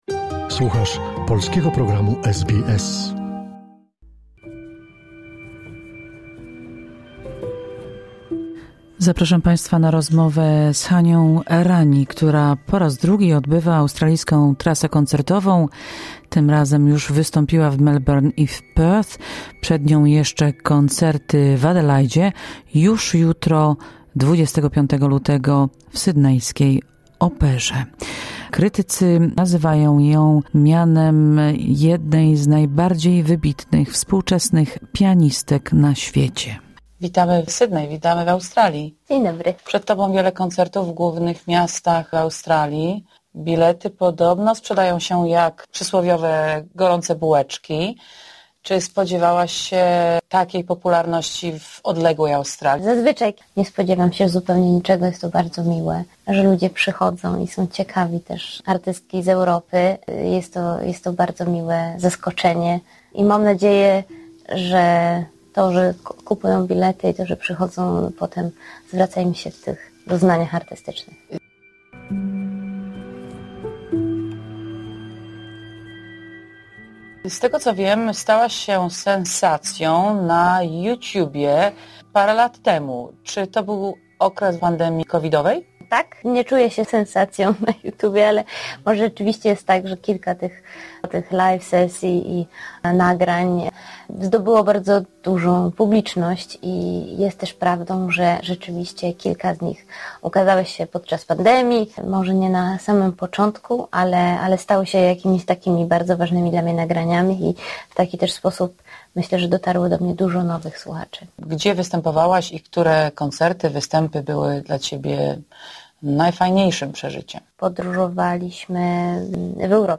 Spotkanie z młodą polską pianistką, kompozytorką i wokalistką, której muzykę określa się mianem nowoczesnej klasyki. Hania Rani – po raz drugi odbywa swoją trasę koncertową w Australii.